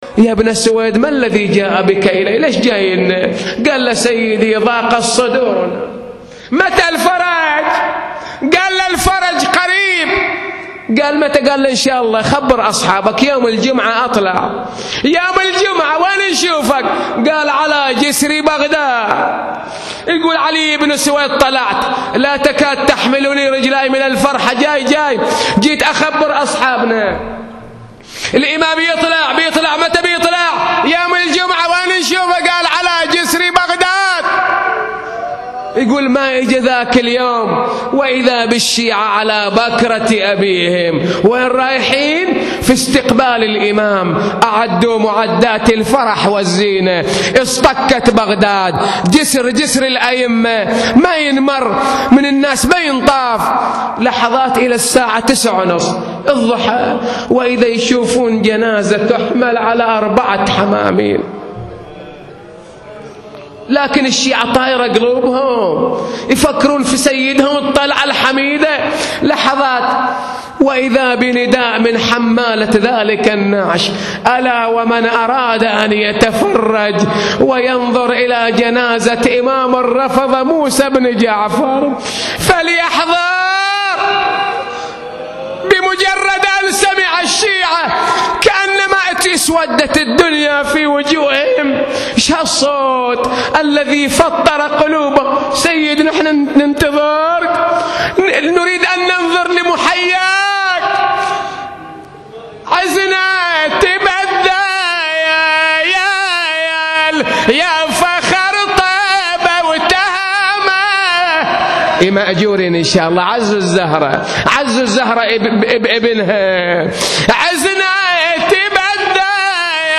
نواعي وأبيات حسينية – 18